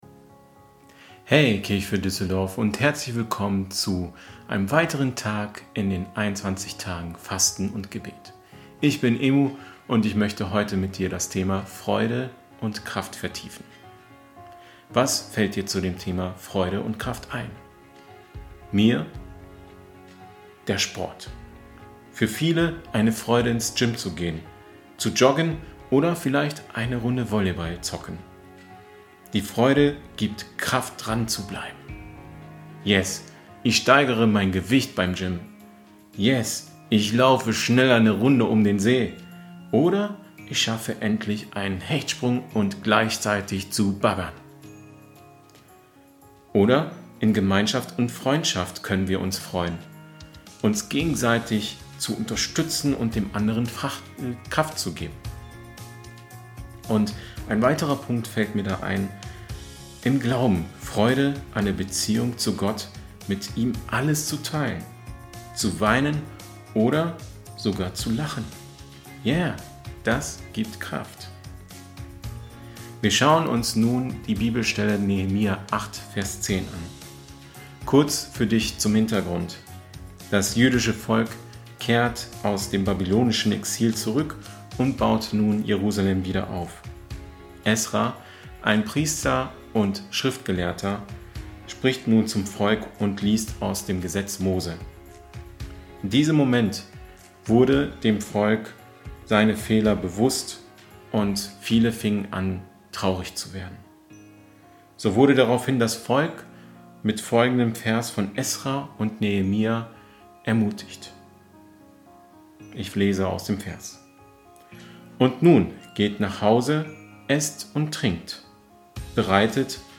Tag 9 der Andacht zu unseren 21 Tagen Fasten & Gebet